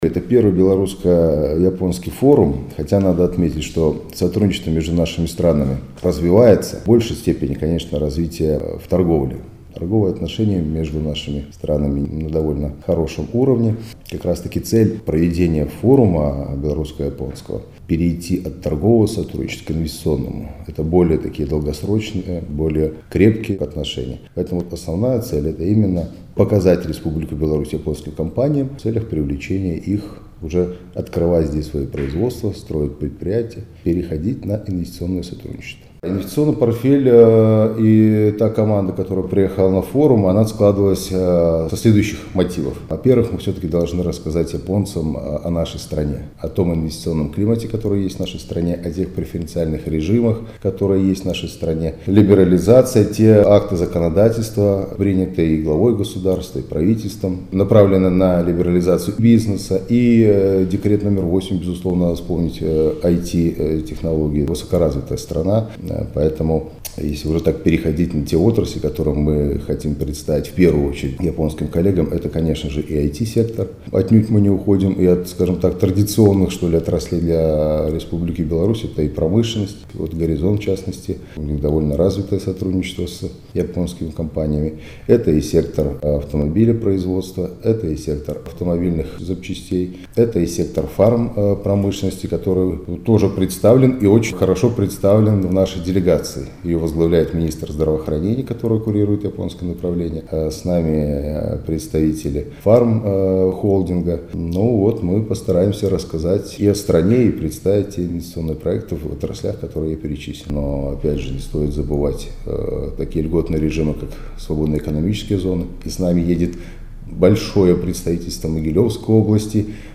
Какие ожидания от первого Белорусско-Японского форума и какой портфель заказов презентует наша страна - международному радио "Беларусь" рассказал исполняющий обязанности директора Национального агентства инвестиций и приватизации Денис Мелешкин.